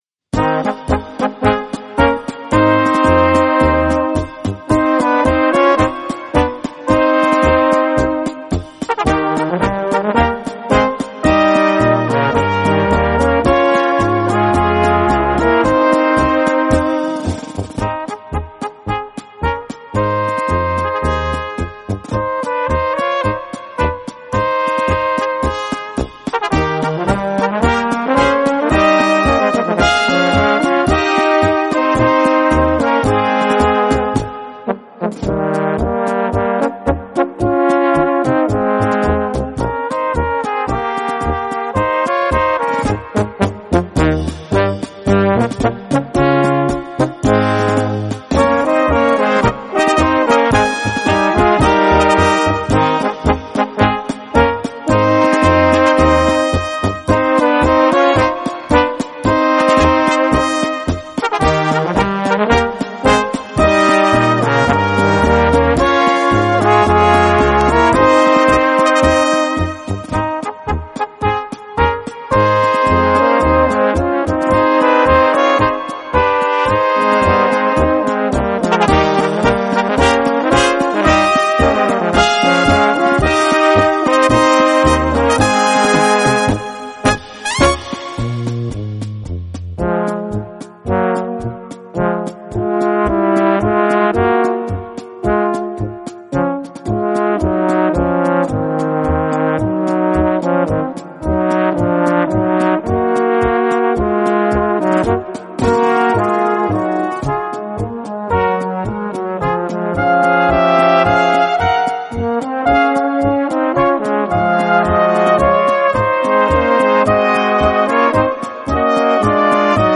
Gattung: Polka für Blasorchester
Besetzung: Blasorchester